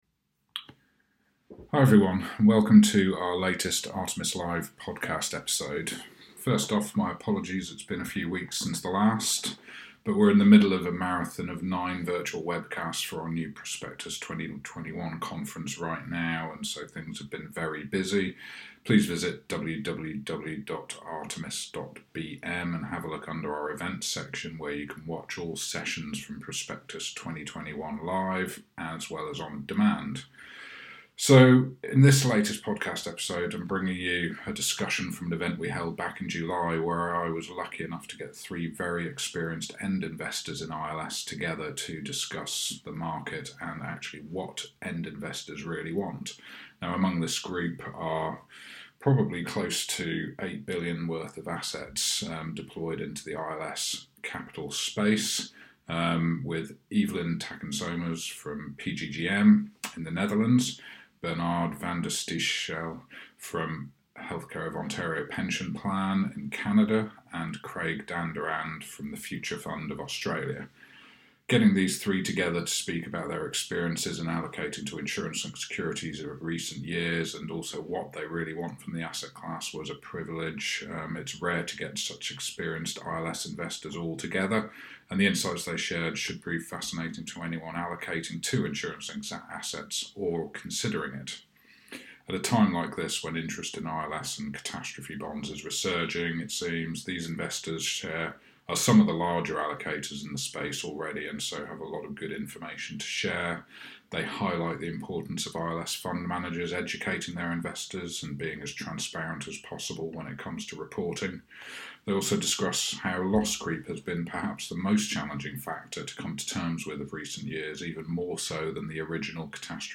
This episode features a panel discussion featuring three leading institutional investors that allocate to insurance-linked securities (ILS) and reinsurance linked investments.